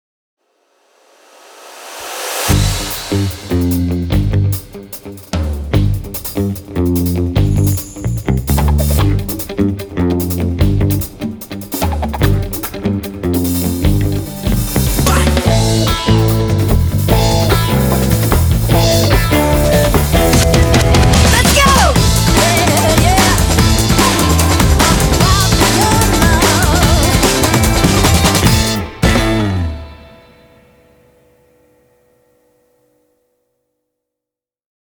TVCM
INSTRUMENTAL ROCK / POPS